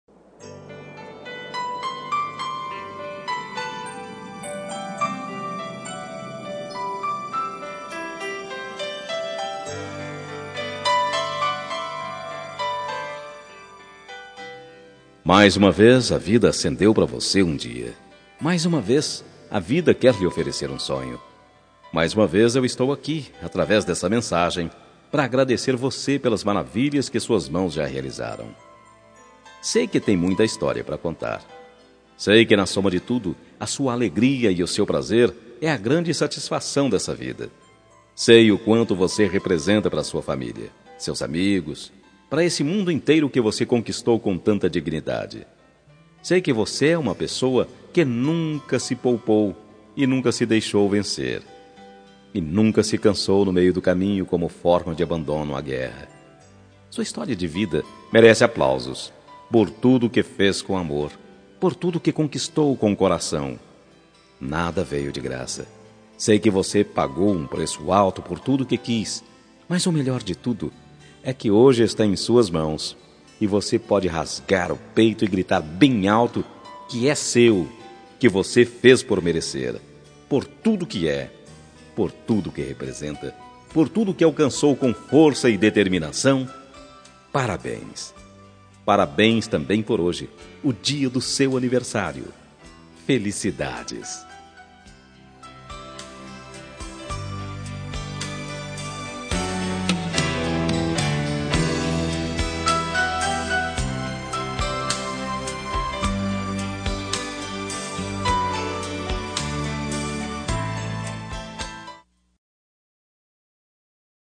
Telemensagem Aniversário de Avó – Voz Masculina – Cód: 2077 – Pessoa Vivida